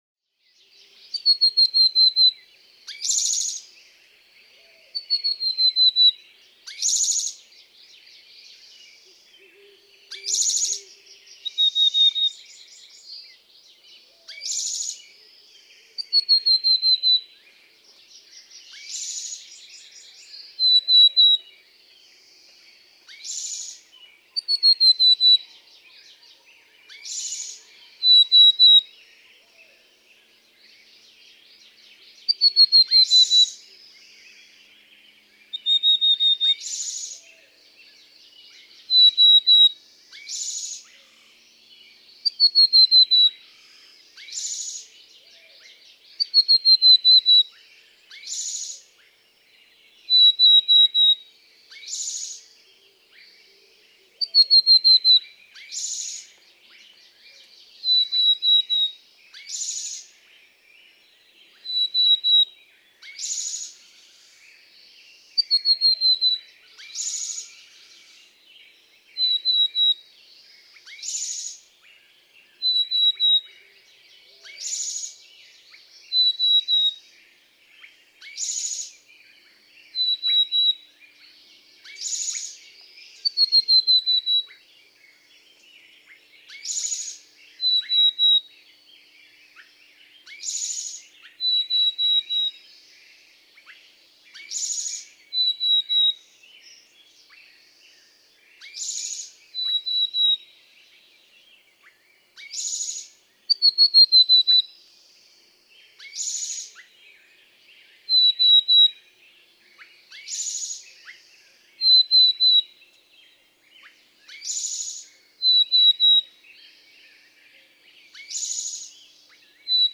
♫257. An Oregon dialect, in the Willamette Valley west of the Cascade Mountains. This male has three different songs that he bounces among during the dawn chorus. With loud spotted towhee.
William L. Finley National Wildlife Refuge, Corvallis, Oregon.
257_Black-capped_Chickadee.mp3